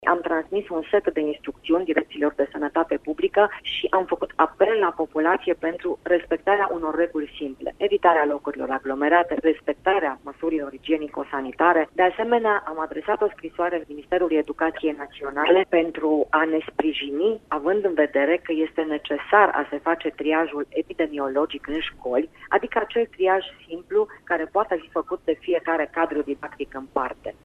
Ministrul Sănătăţii, Sorina Pintea, a declarat că România este în pragul unei epidemiei de gripă
Ea a vorbit şi despre unele măsuri ce pot fi luate pentru prevenirea îmbolnăvirilor: